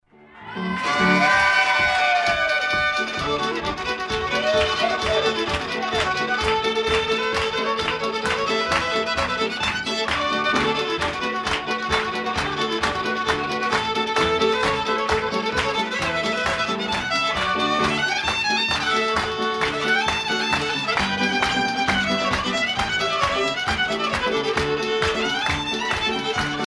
circonstance : carnaval, mardi-gras
Pièce musicale éditée